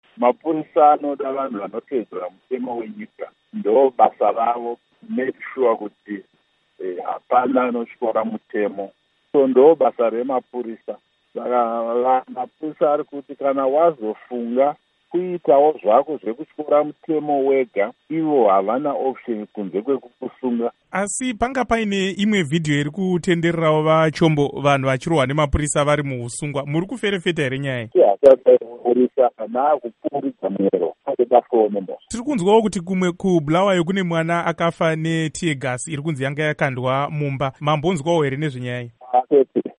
Hurukuro naVaIgnatious Chombo